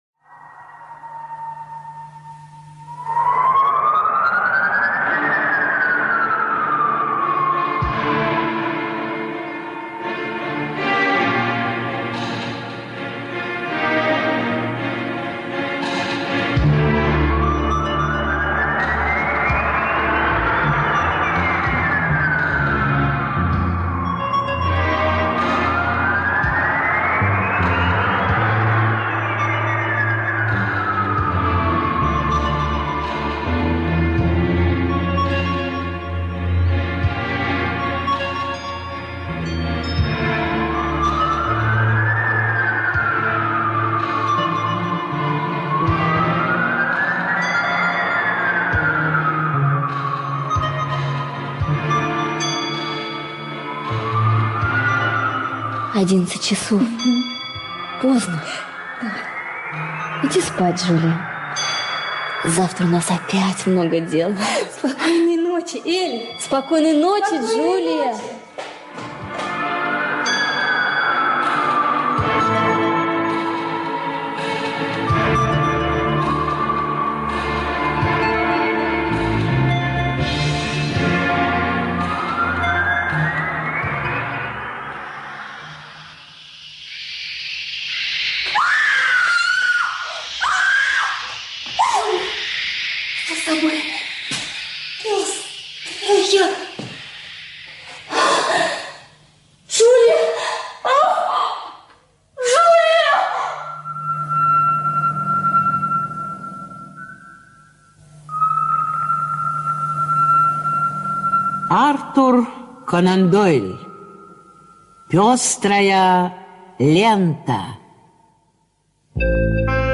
Пестрая лента - аудио рассказ Артура Конан Дойла - слушать онлайн